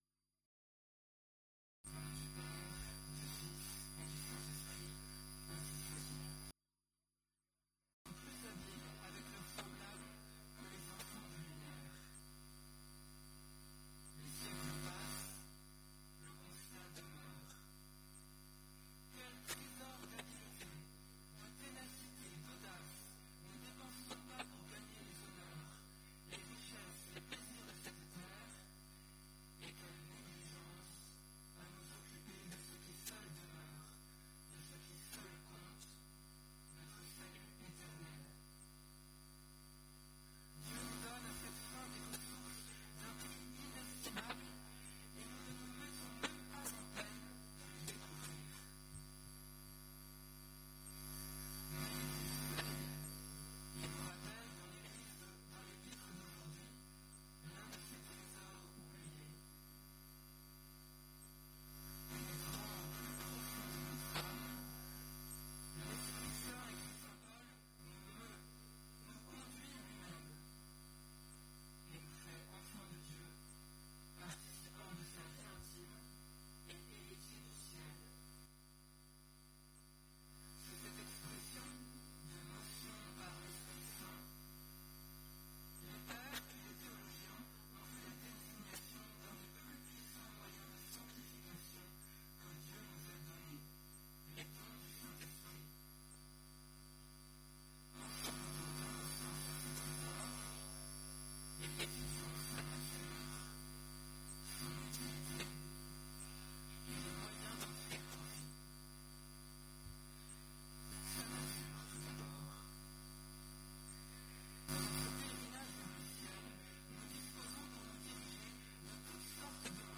Occasion: Huitième dimanche après la Pentecôte
Type: Sermons